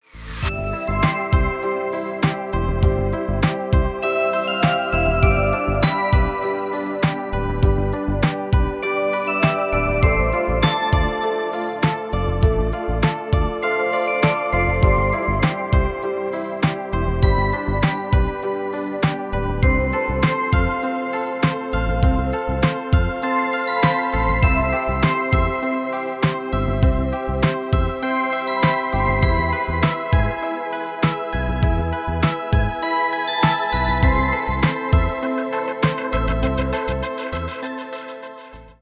мелодичные , инструментальные , без слов
нежные